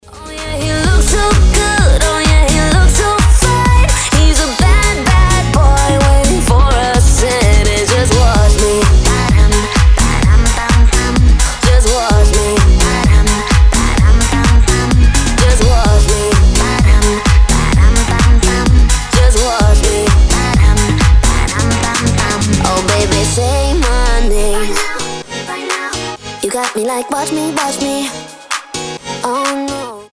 Категория : Танцевальные рингтоны